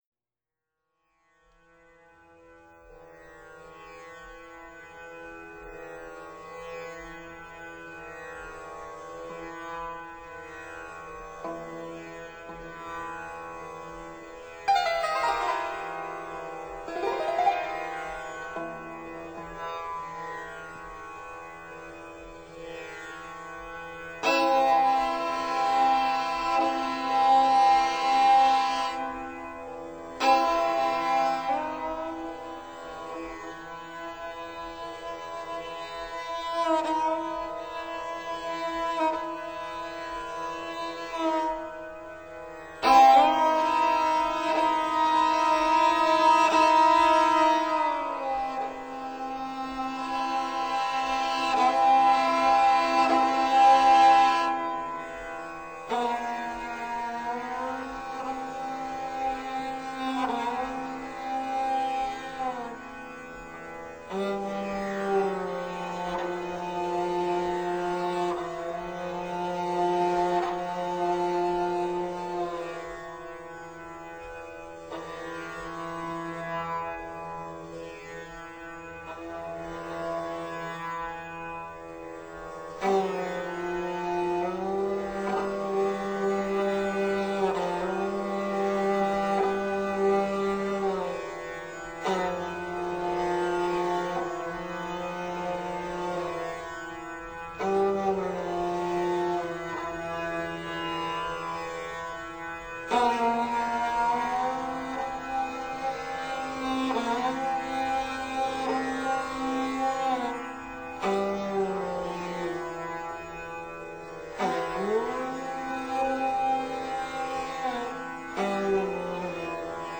narayan_gujritodi.wma